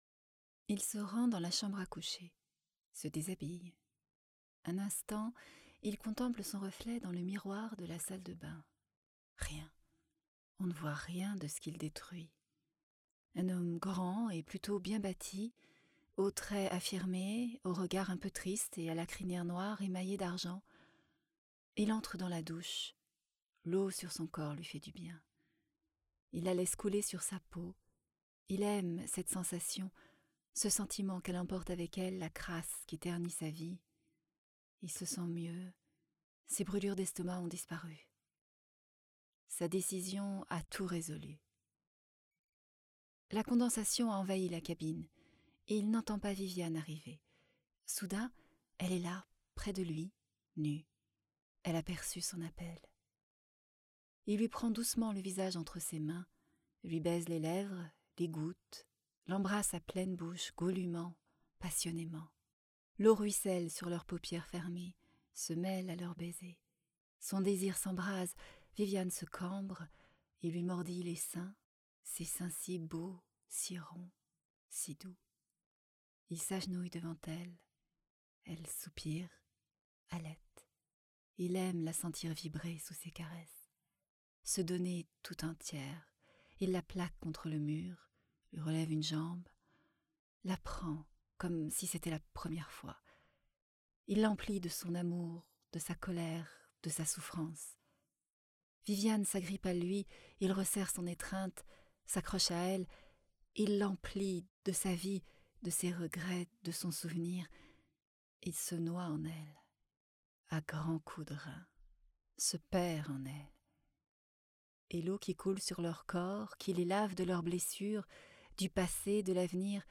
Extraits voix off.